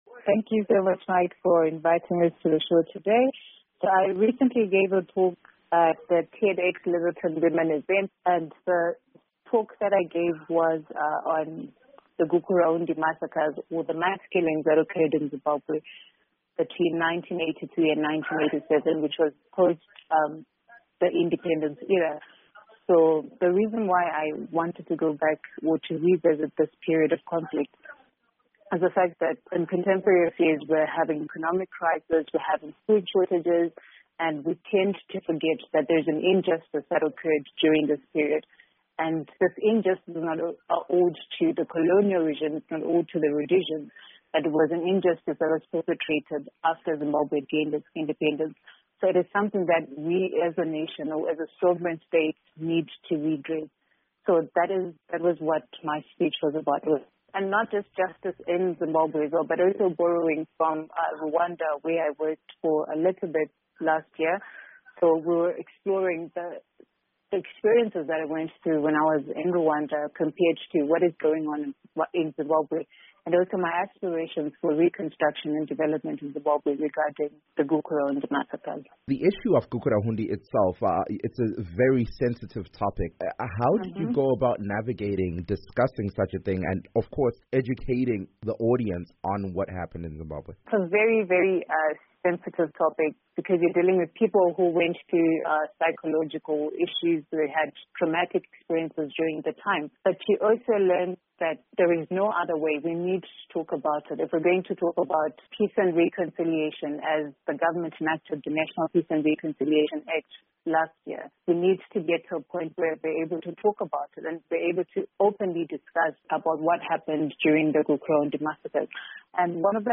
South Africa based Zimbabwean delivers Tedtalk